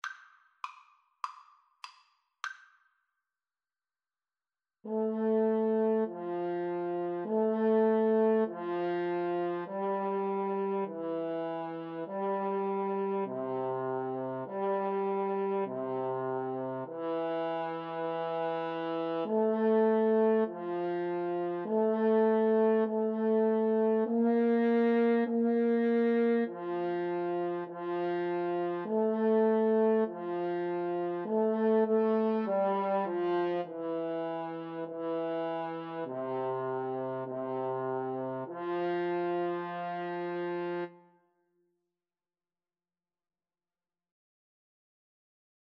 Espressivo
Arrangement for French Horn Duet